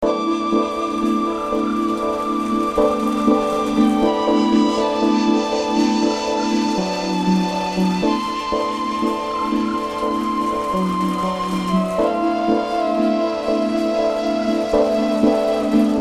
描述：Windbell playing music during rain
标签： wind drops house forest water rain nature windbell bell relaxing fieldrecording ambient night sound
声道立体声